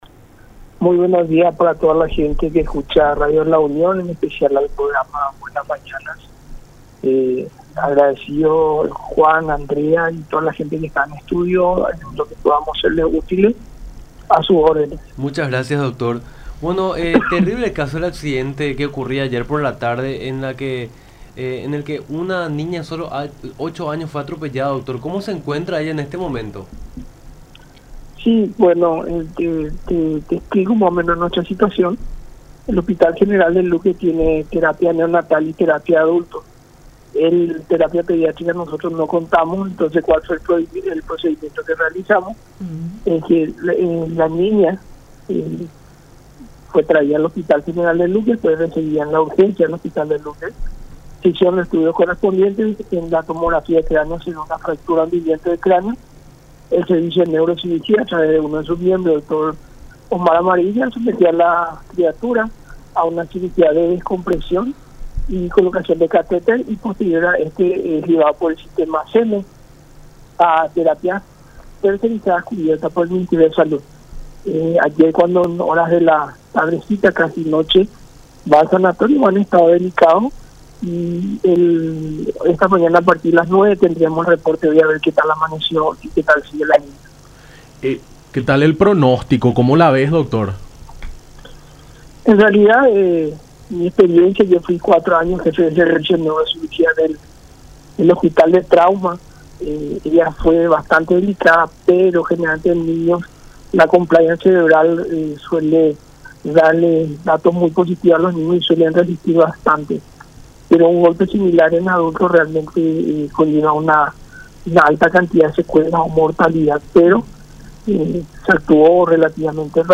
en contacto con Nuestra Mañana por La Unión.